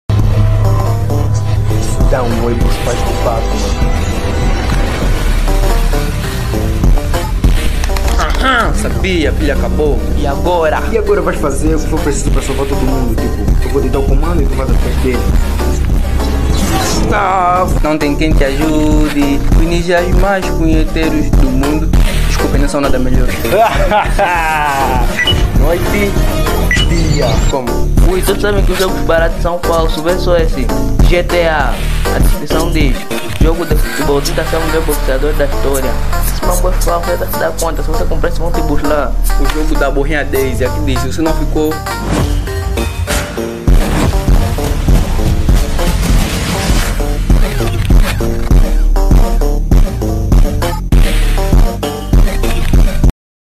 DUBLAGEM ANGOLANA: O INCRÍVEL MUNDO DE GUMBALL DUBLADO EM PT AO